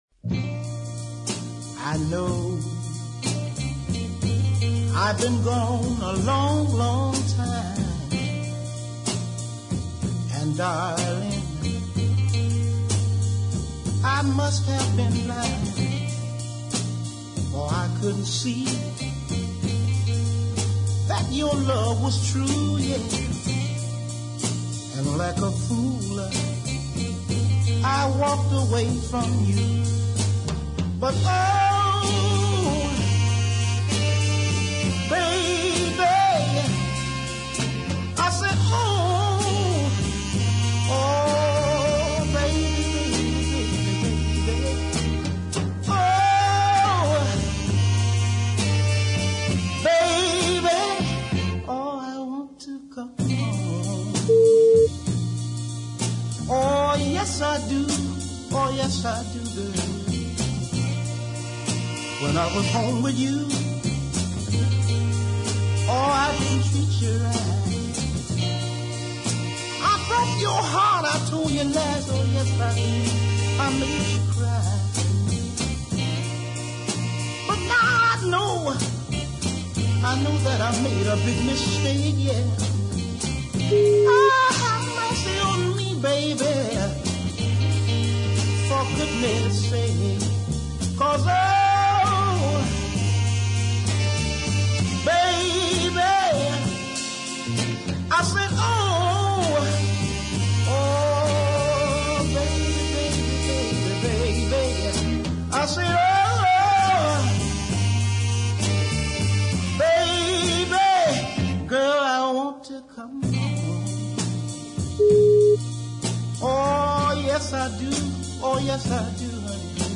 deep classic
in Muscle Shoals
But both showed what a fine high baritone voice he had.
Over a subdued organ